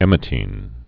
(ĕmĭ-tēn)